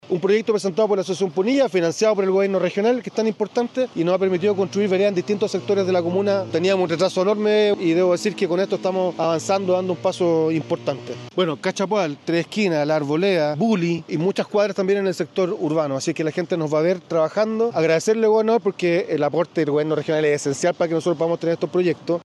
Por su parte, el alcalde Rubén Méndez destacó el impacto que esta obra tendrá en la comunidad: “Este es un paso significativo para nuestra comuna. Estamos avanzando con nuevas veredas en sectores como Cachapoal, Tres Esquinas y Buli, recuperando años de retraso en infraestructura peatonal. Agradecemos al Gobierno Regional por el respaldo que nos permite concretar estas obras tan necesarias”.
Alcalde-Ruben-Mendez.mp3